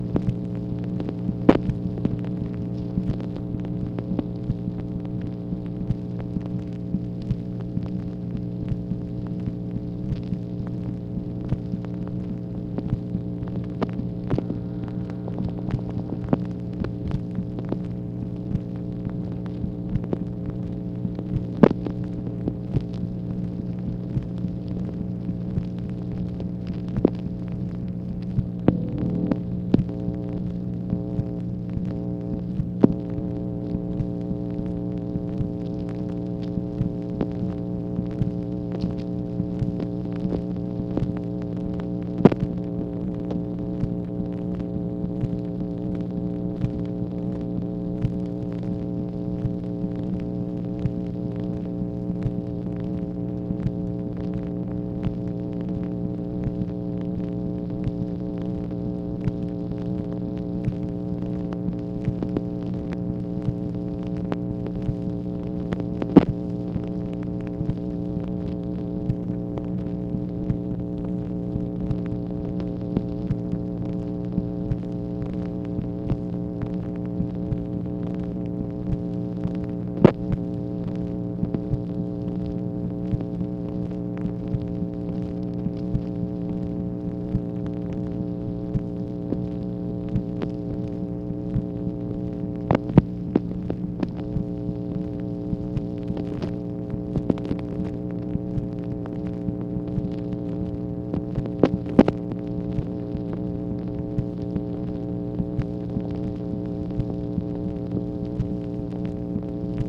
MACHINE NOISE, September 30, 1965
Secret White House Tapes